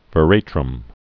(və-rātrəm)